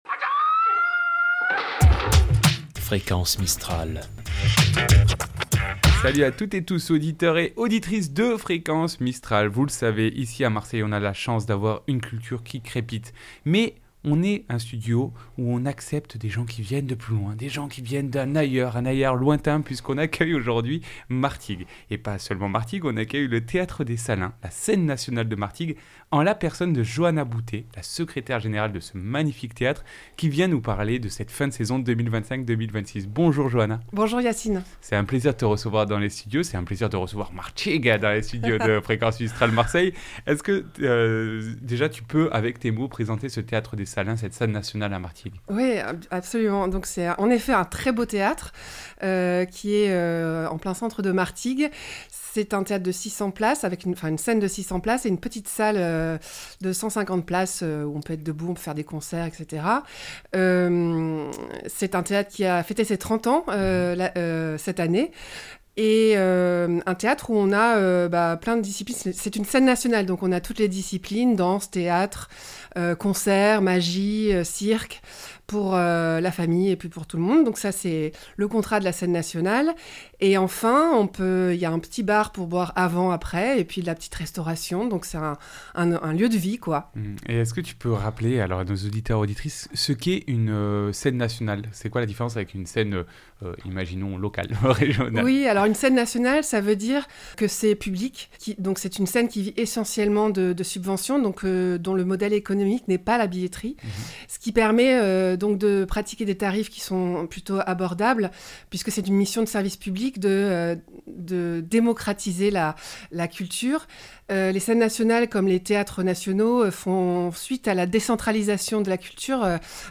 Itw Theatre des Salins Martigues.mp3 (16.9 Mo)